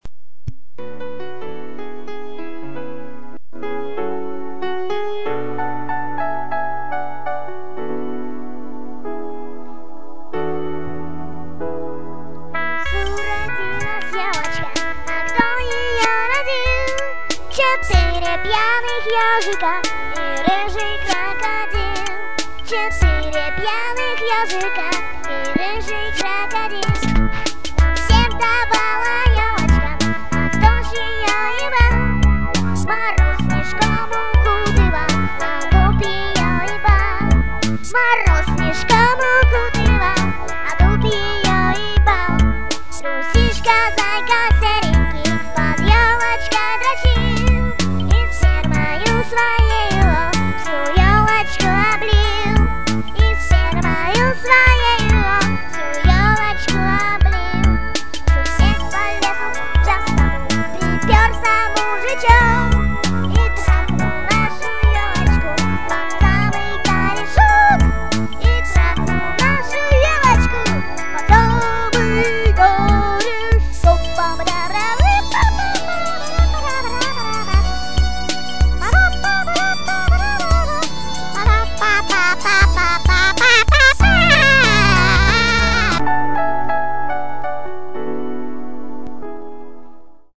вот давно уже записал песенку( внимание!!! мат!!!) оцените!